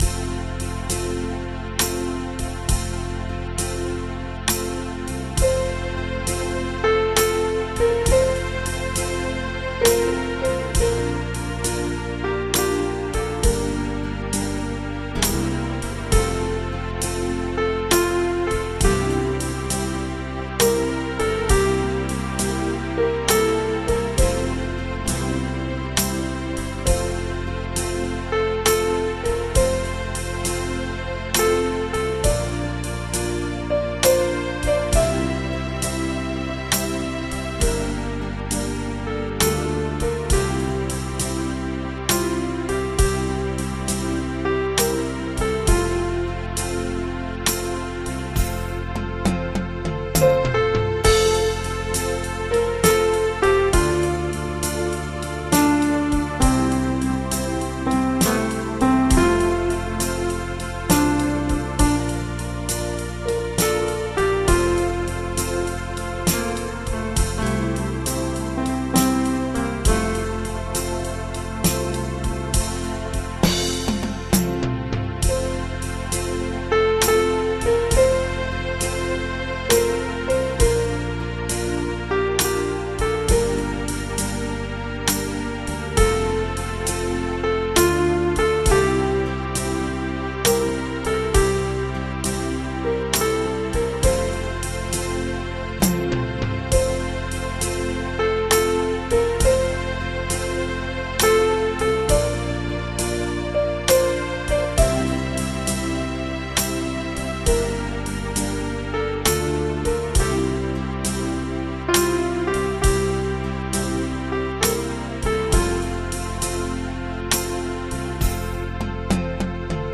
Audio Bass Midi
song1doichochuaBass.mp3